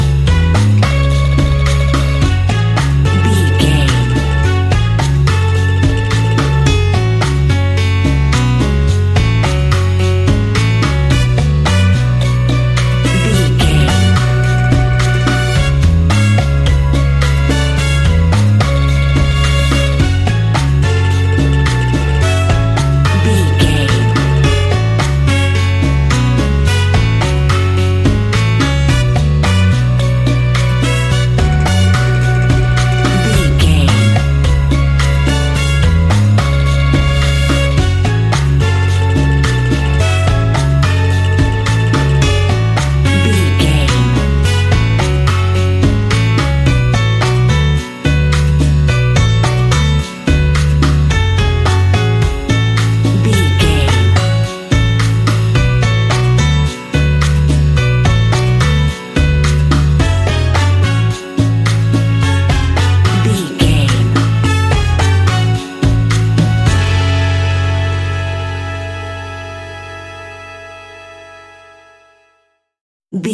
Aeolian/Minor
F#
calypso
steelpan
drums
percussion
bass
brass
guitar